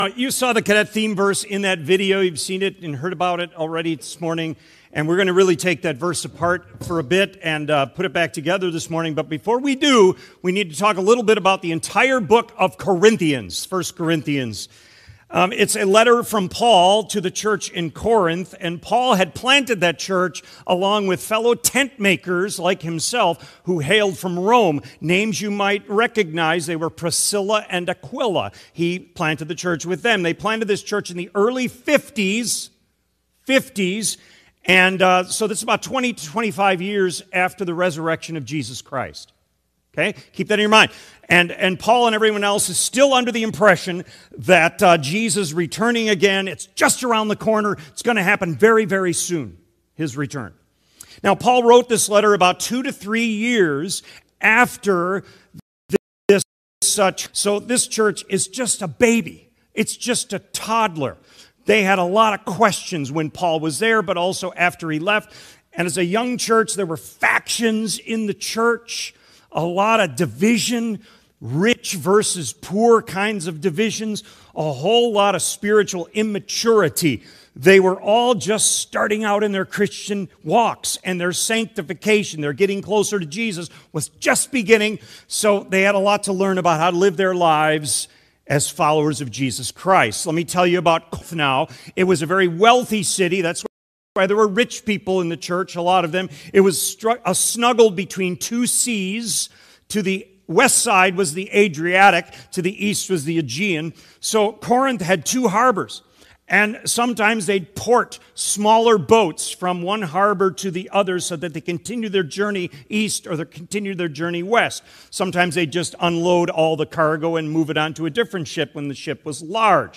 Sermon Recordings | Faith Community Christian Reformed Church
“Stand Firm” January 25 2026 A.M. Service